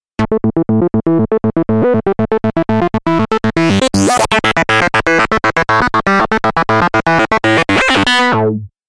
合成器琶音
描述：短琶音合成循环。 使用的仪器是UHe的ReproOne合成器，它是Sequential Circuits Pro One的数字模型。 环路的第一部分采用慢速滤波器调制。 环路的第二部分内置了合成器内置的波形文件夹失真。
Tag: 合成器 模拟 锯齿波 wavefolder 过滤器